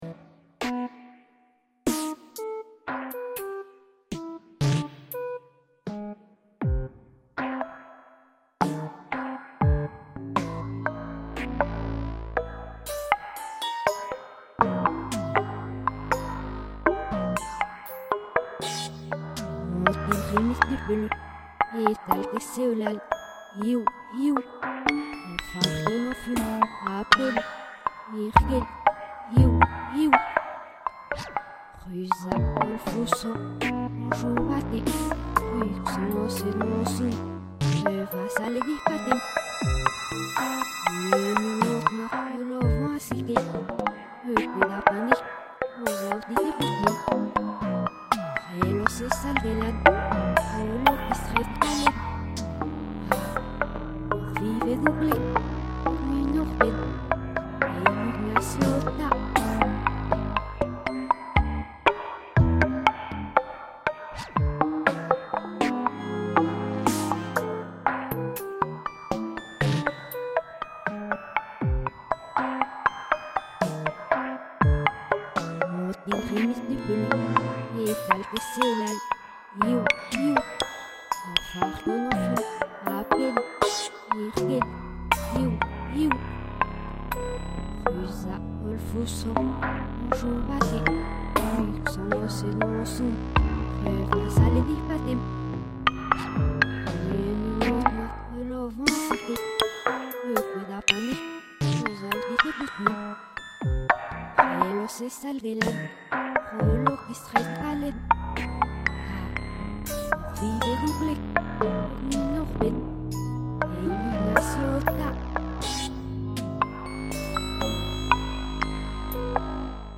El material principal de esta composición es la propia voz humana, que recita el texto de dos maneras diferentes: una leída y otra pronunciando exclusivamente los fonemas.
Hay, además, un tema recurrente que es la transcripción musical del verbo ouïs (oíd!), que es el grupo de fonemas más repetido en el caligrama.
Aparece también un ostinato rítmico que procede de un grupo de fonemas traspolado a instrumentos sintetizados de percusión.
En el centro de la variación aparece dos veces el texto recitado en sentido inverso (retrogradación).